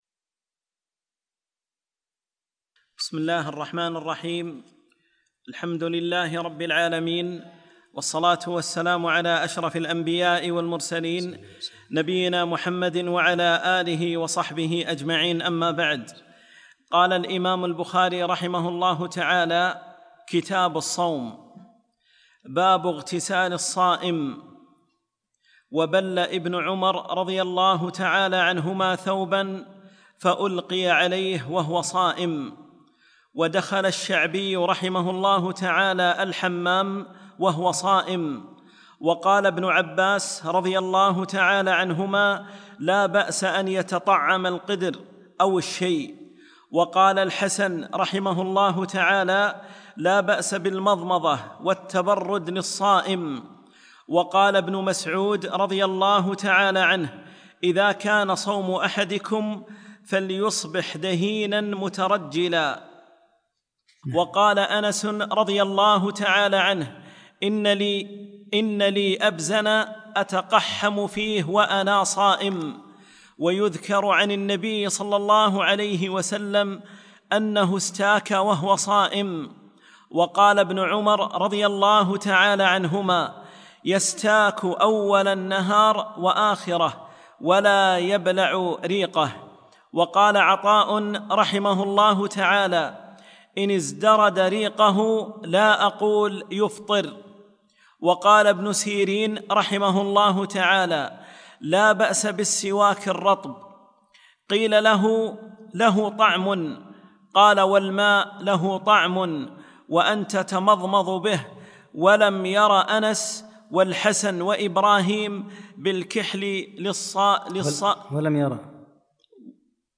4- الدرس الرابع